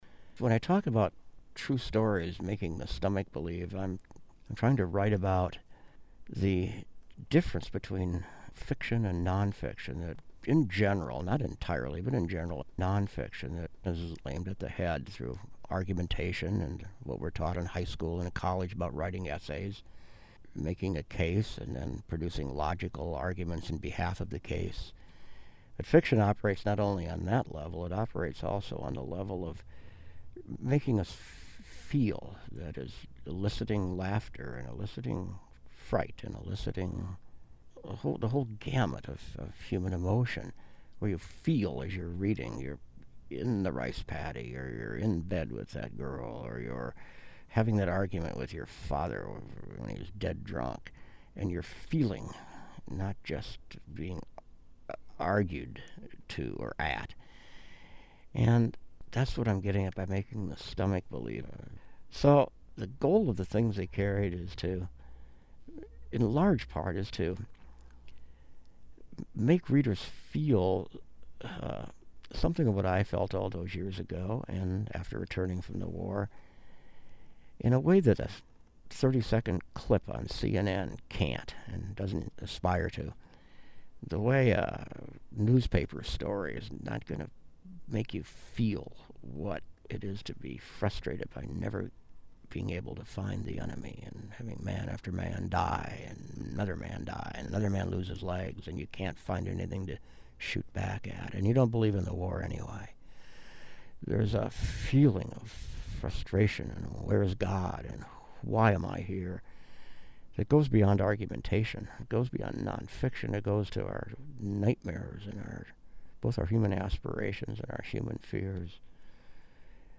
Transcript of Tim O'Brien